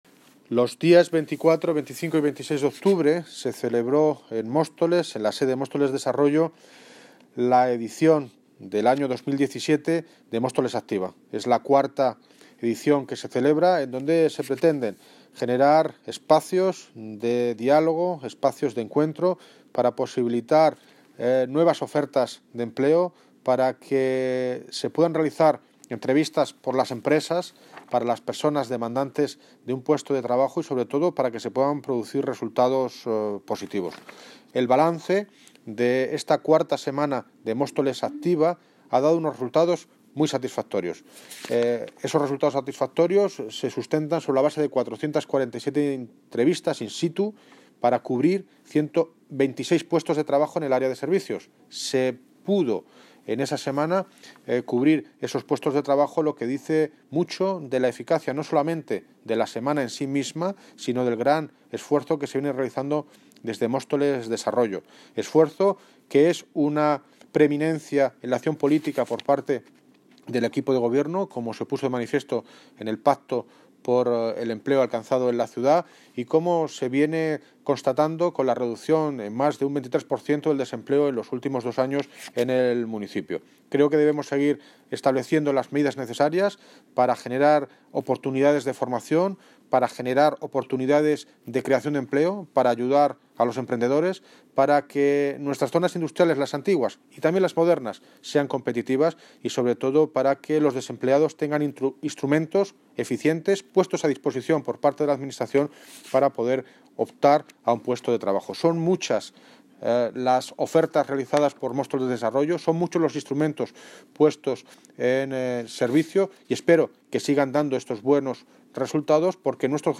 Audio - David Lucas (Alcalde de Móstoles) Sobre balance Semana Móstoles Activa